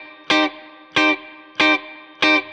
DD_StratChop_95-Cmin.wav